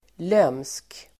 Uttal: [löm:sk]